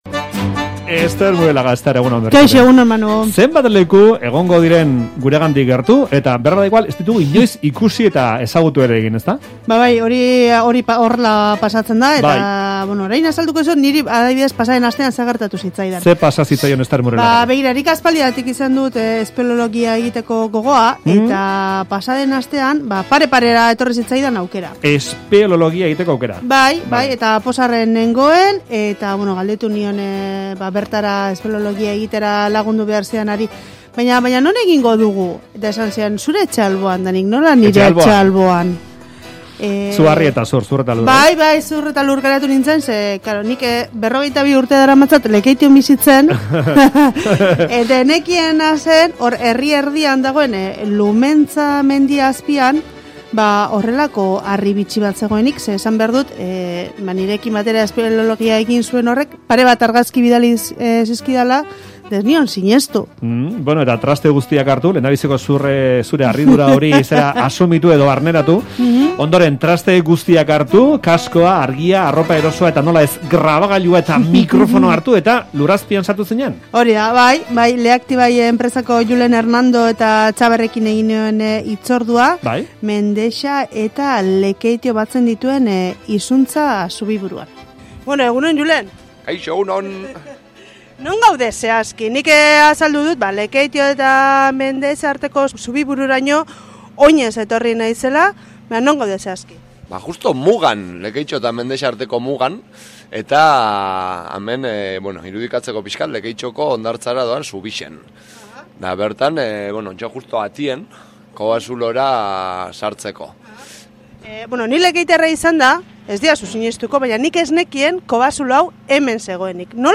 Audioa: Lekeitioko Zubiburu kobazuloa bisitatu du Faktoriak Leaktibaikoen laguntzarekin